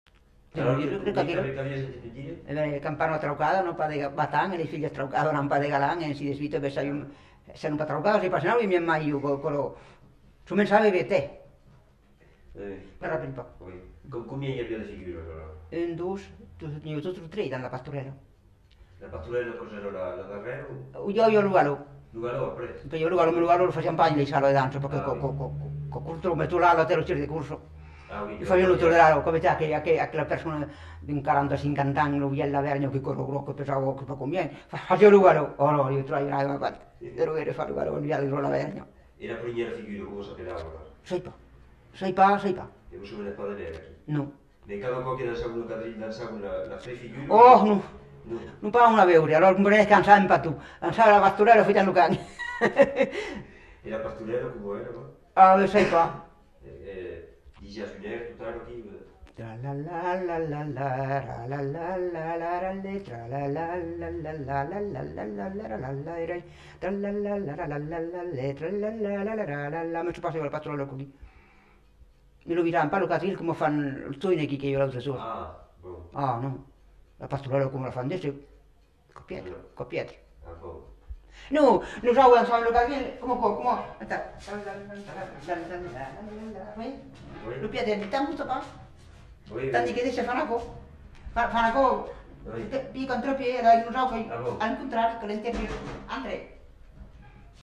Aire culturelle : Haut-Agenais
Lieu : Castillonnès
Genre : chant
Effectif : 1
Type de voix : voix de femme
Production du son : fredonné
Danse : quadrille
L'interprète danse en même temps.